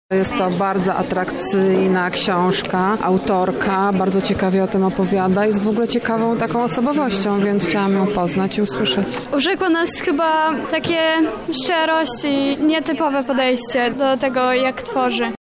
Uczestnicy spotkania w Ośrodku Brama Grodzka – Teatr NN byli pod wrażeniem wydawnictwa.